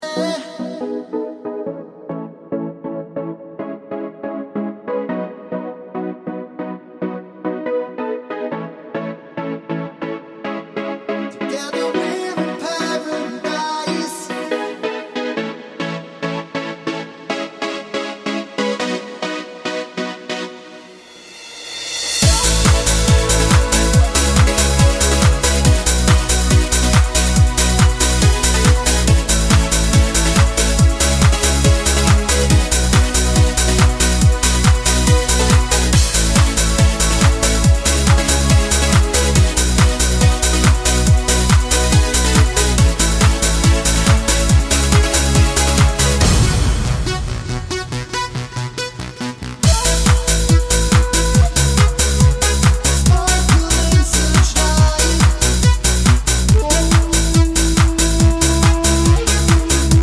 no Backing Vocals Dance 3:22 Buy £1.50